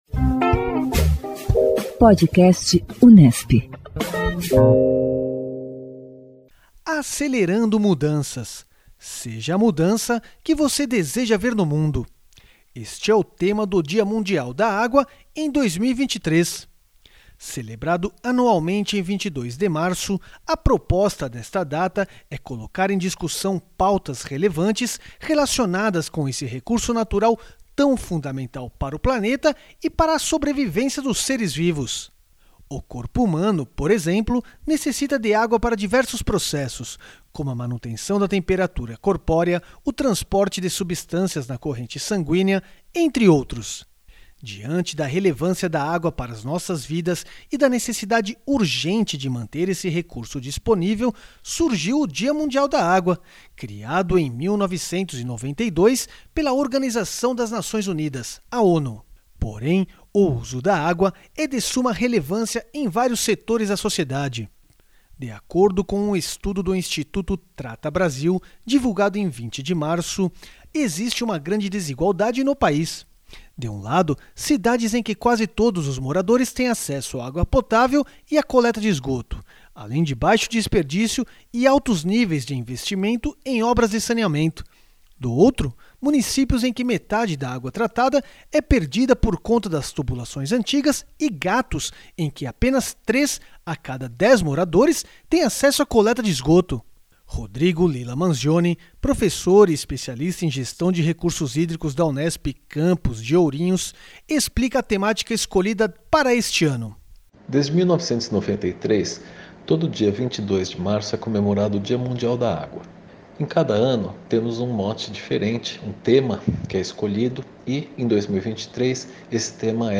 O PodAcqua traz trechos de entrevistas com especialistas da Unesp e de outras instituições, nas mais diferentes áreas do conhecimento, com atenção especialmente voltada à gestão responsável dos recursos hídricos.